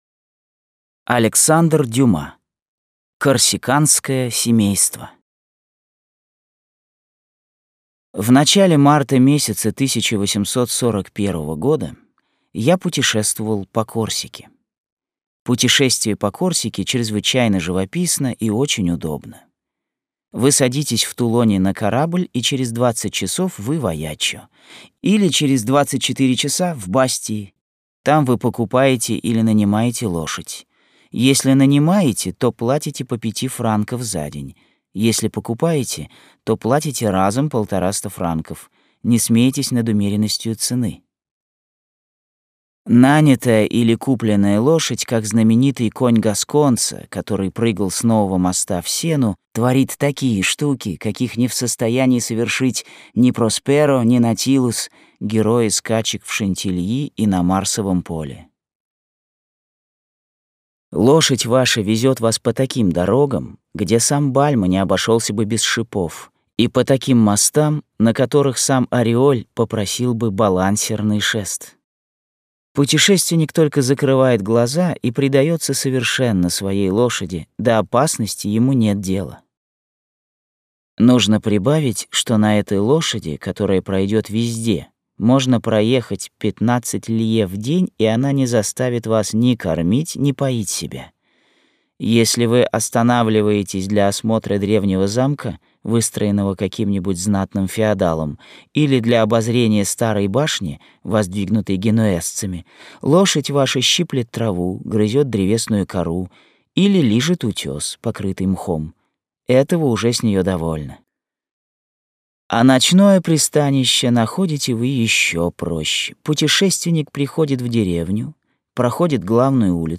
Аудиокнига Корсиканское семейство | Библиотека аудиокниг